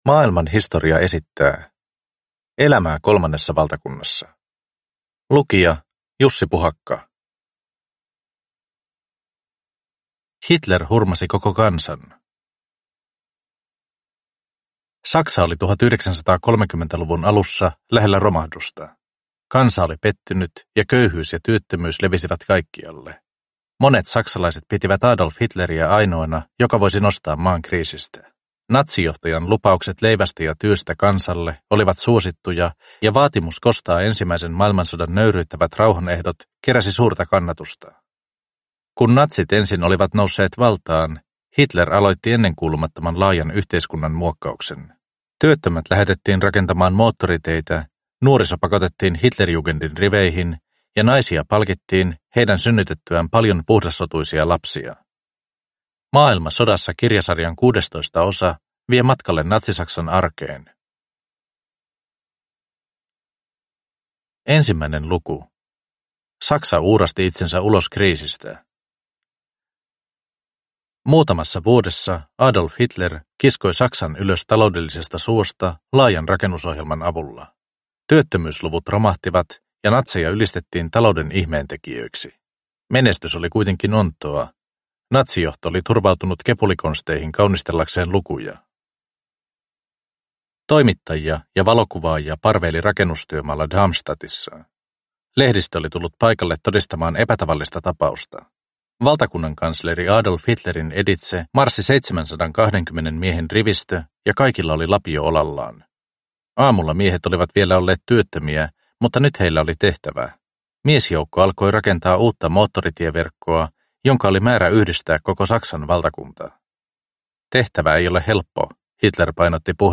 Elämää Kolmannessa valtakunnassa (ljudbok) av Maailman Historia